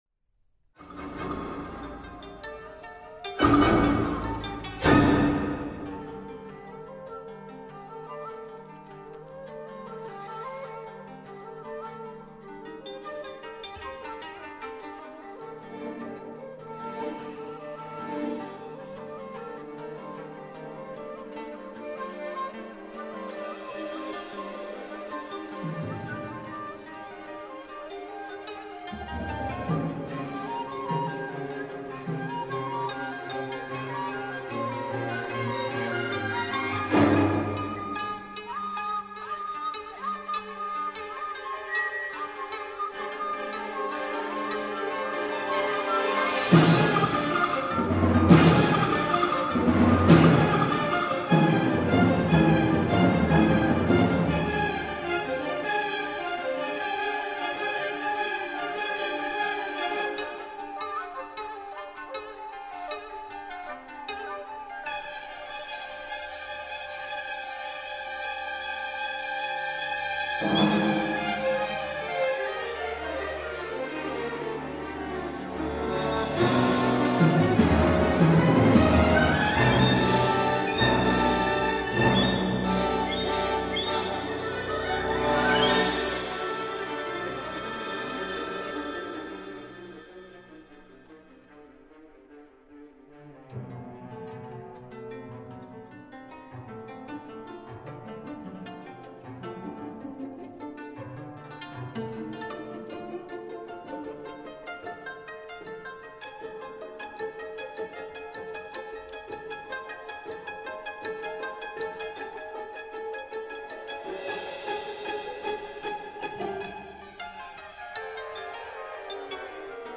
shakuhachi, 21-string koto & orchestra